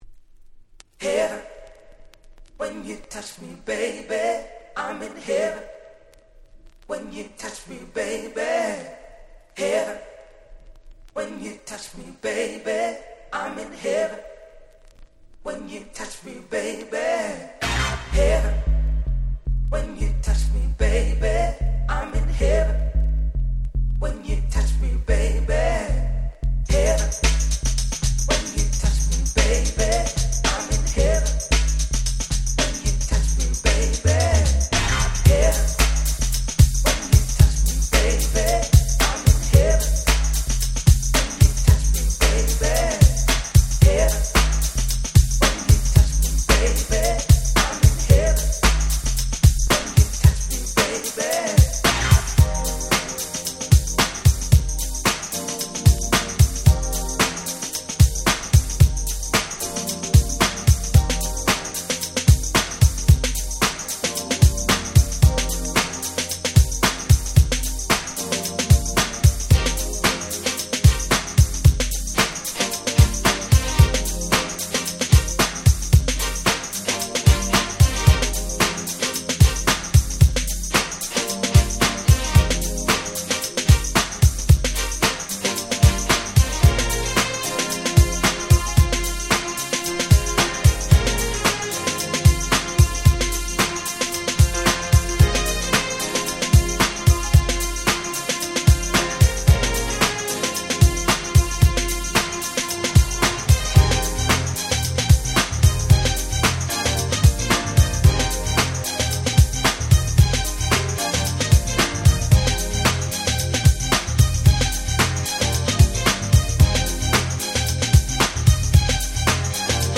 90' UK Street Soul Classics !!